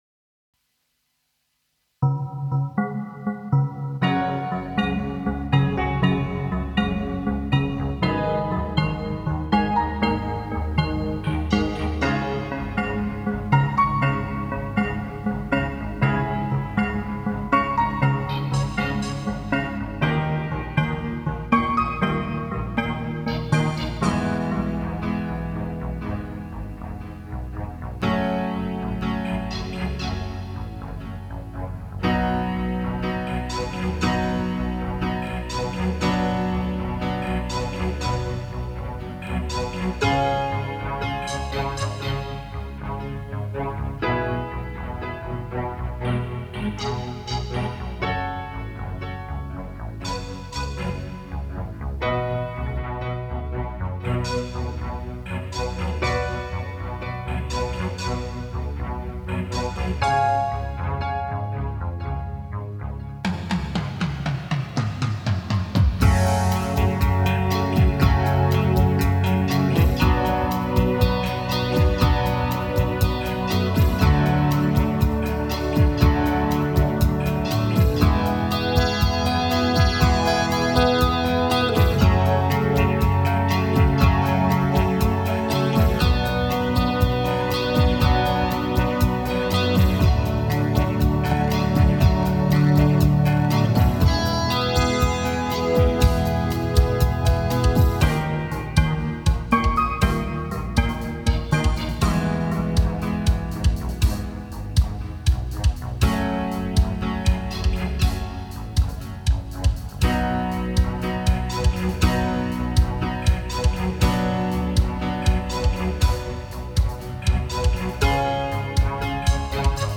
Ich hänge das Instrumental ohne NANA dazu, damit jeder von euch den Text selbst "reinbeißen" kann und etwaige Änderungen und Verbesserungen vorschlagen kann.